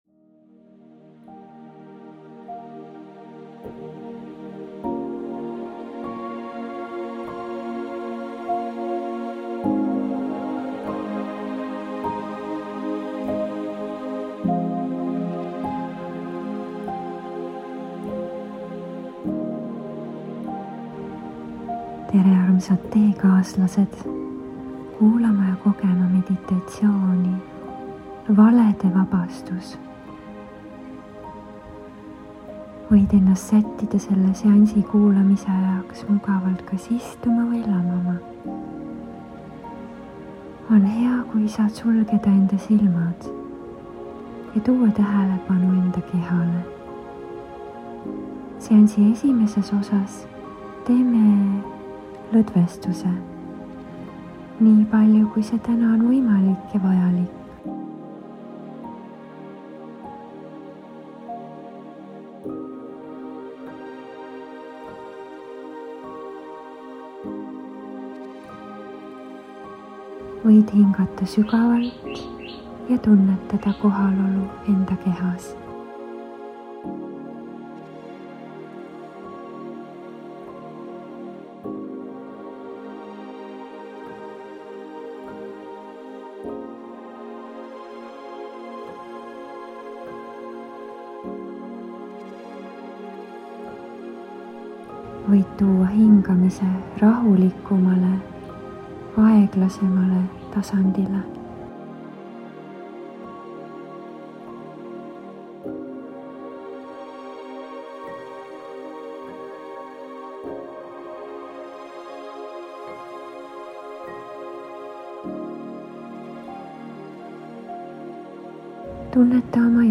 Valede vabastuse meditatsioon – tõe ja kergenduse loomine